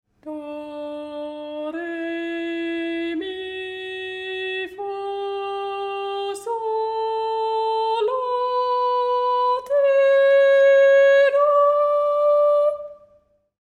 Audio02D-duuri.mp3